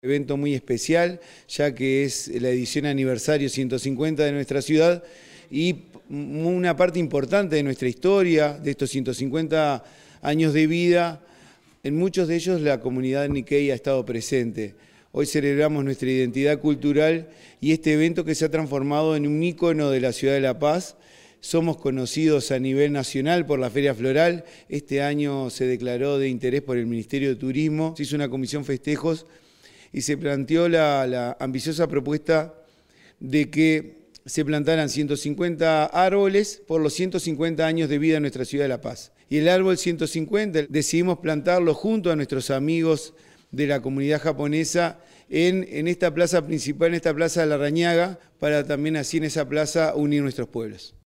bruno_fernandez_alcalde_de_la_paz_0.mp3